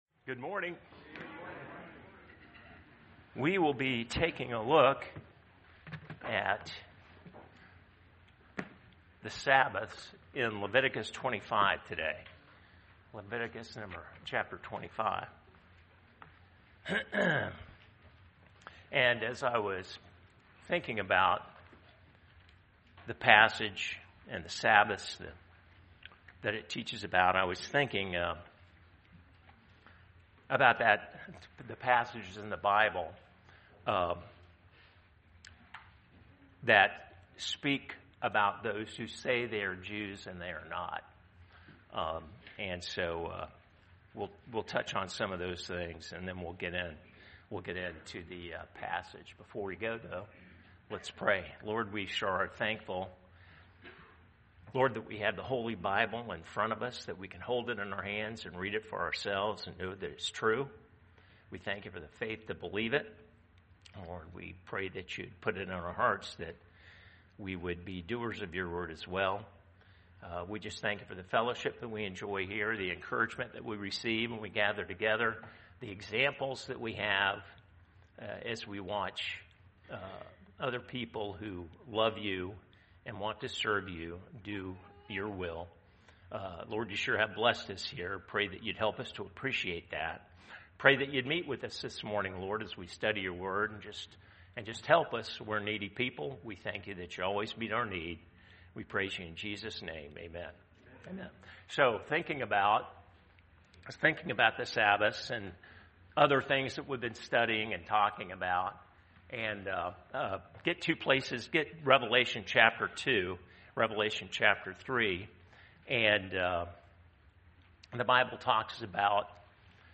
Leviticus 25 – The Sabbath | Sunday School